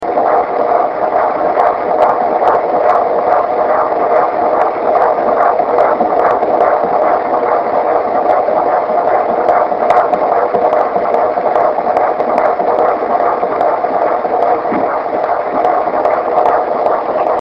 I have a digital recording of the heartbeat, but I can't figure out how to post it.
heartbeat.mp3